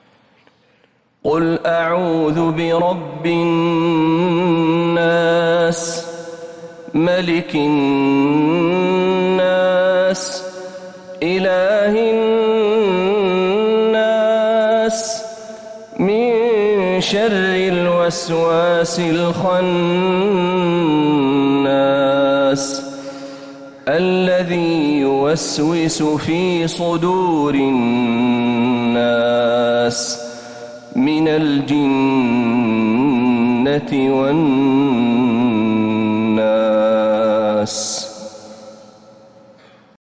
سورة الناس | شعبان 1447هـ > السور المكتملة للشيخ محمد برهجي من الحرم النبوي 🕌 > السور المكتملة 🕌 > المزيد - تلاوات الحرمين